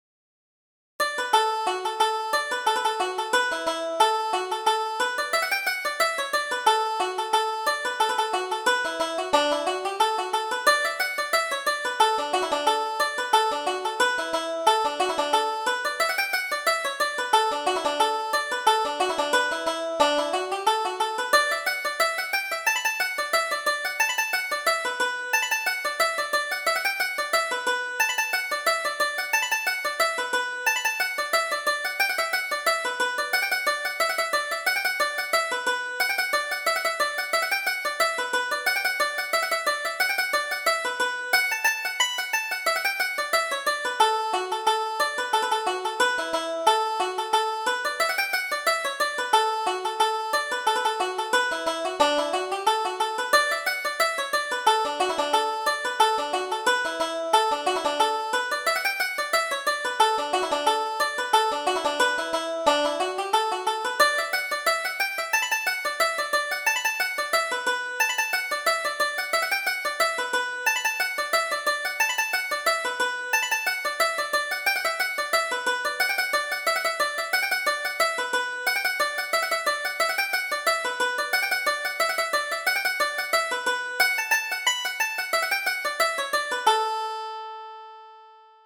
Reel: The Bucks of Oranmore